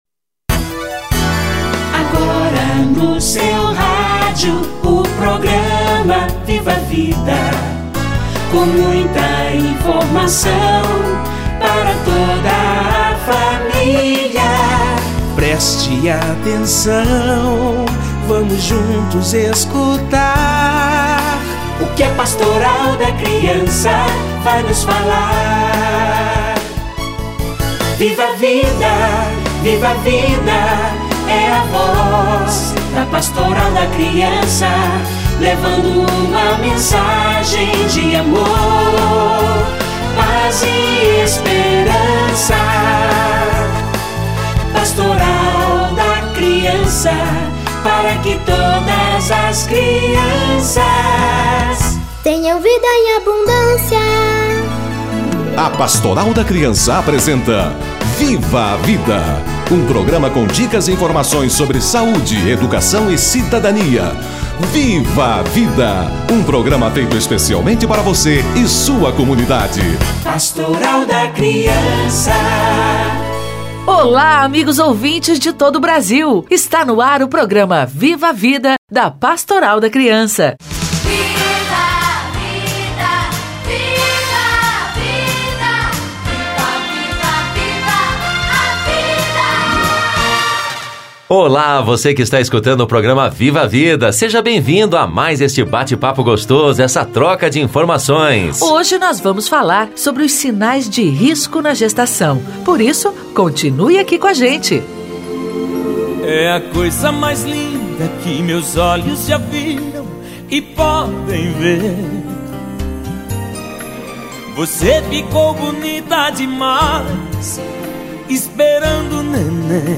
Sinais de risco - Entrevista